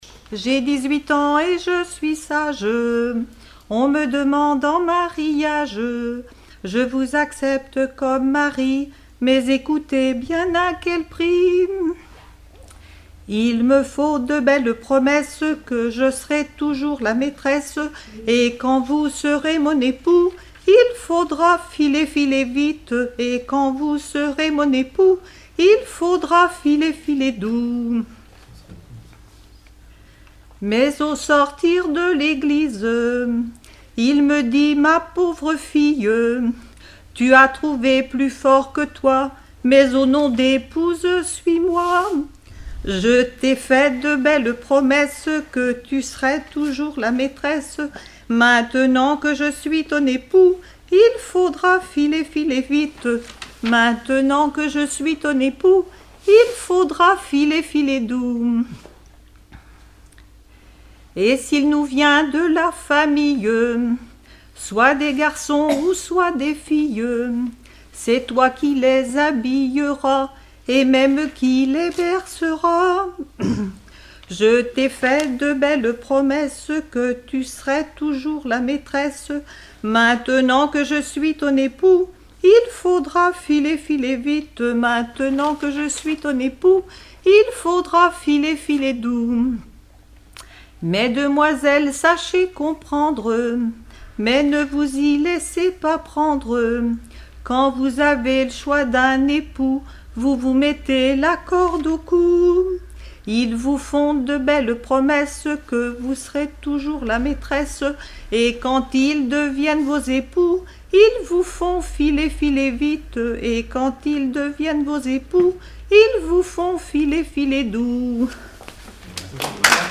Genre strophique
enregistrement d'un collectif lors d'un regroupement cantonal
Pièce musicale inédite